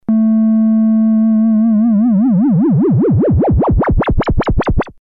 かなり(^_^;　右へ回すほど変調が深くなる。